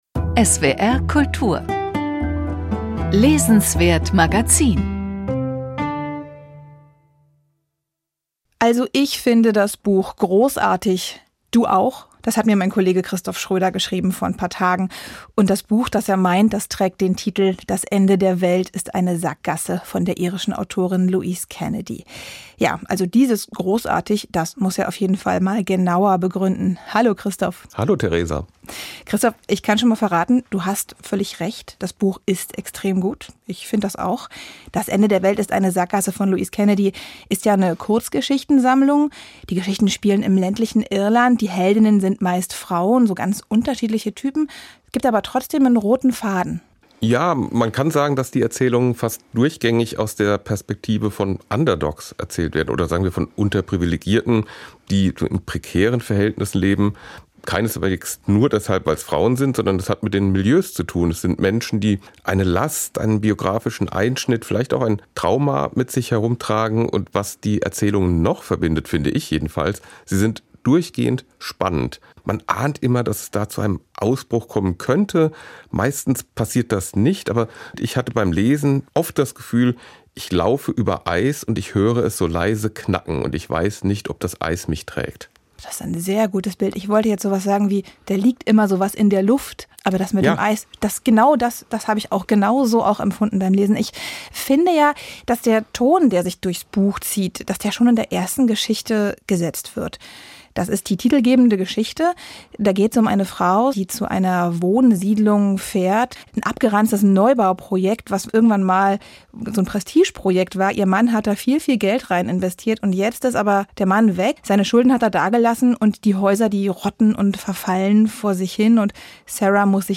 Das Gespräch führte